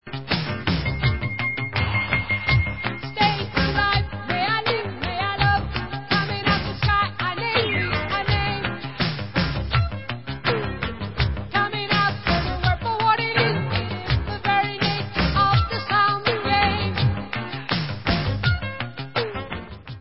Pop/Symphonic